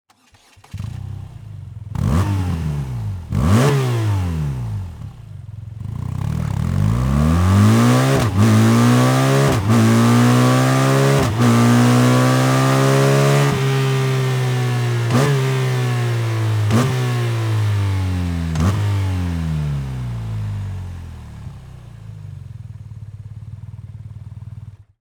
Son avec l’échappement d’origine :
Stock-Exhaust-BMW-R12.wav